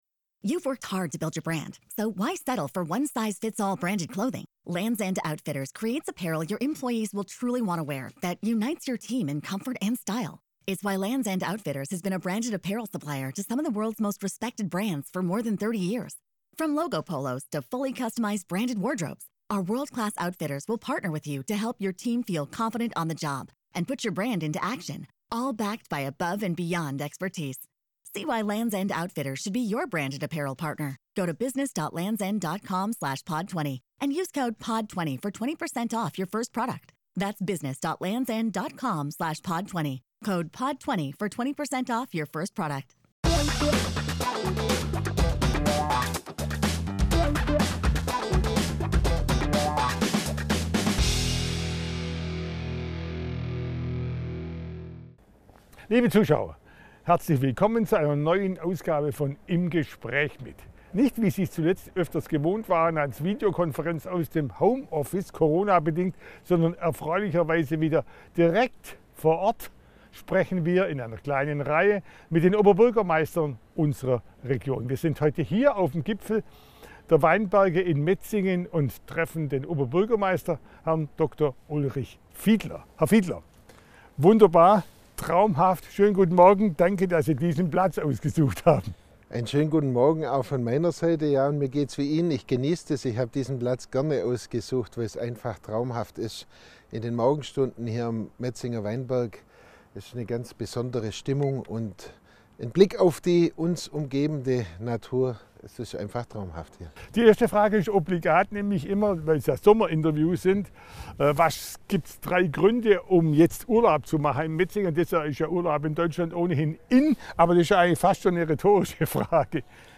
Das Sommerinterview 2020 mit Dr. Ulrich Fiedler, Oberbürgermeister von Metzingen im Kreis Reutlingen.